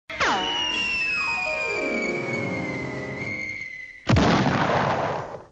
Cartoon Falling Sound Effect Free Download
Cartoon Falling